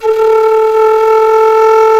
Index of /90_sSampleCDs/INIS - Opium/Partition H/DIZU FLUTE
DIZI02A2.wav